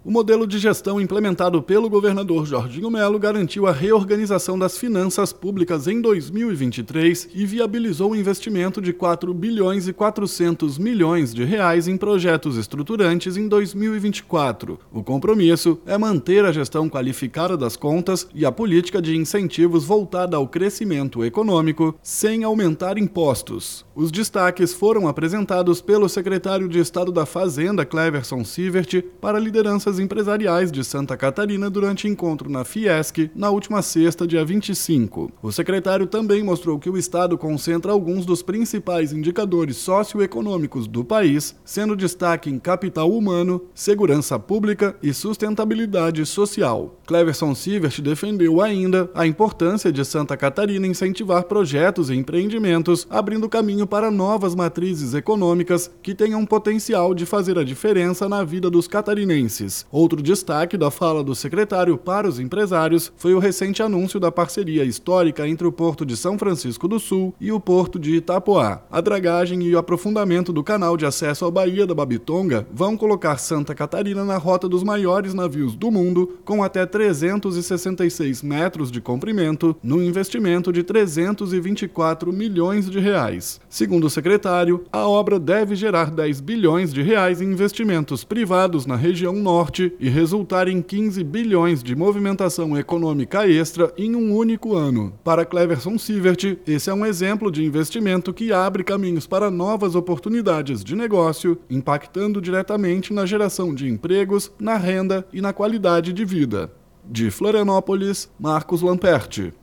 BOLETIM – Contas públicas e desempenho econômico de SC são destaques em encontro de lideranças empresariais na Fiesc
Os destaques foram apresentados pelo secretário de Estado da Fazenda, Cleverson Siewert, para lideranças empresariais de Santa Catarina durante encontro na Fiesc, na última sexta, 25.